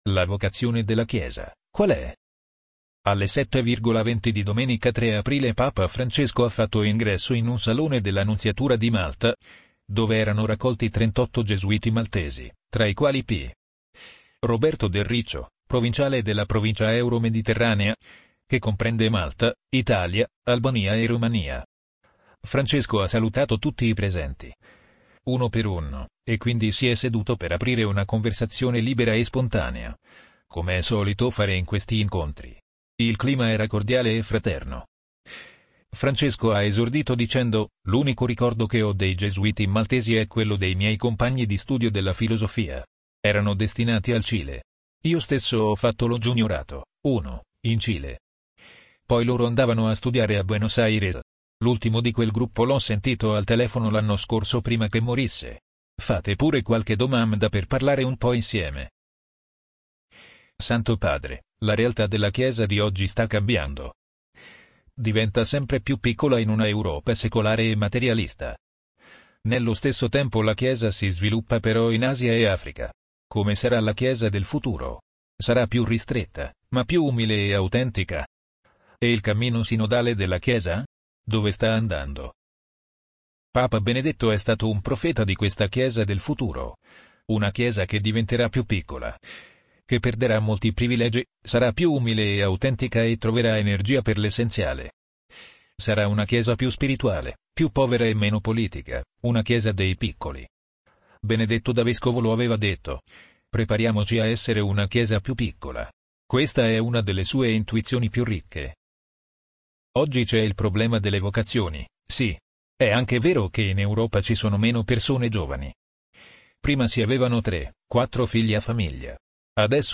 amazon_polly_28337.mp3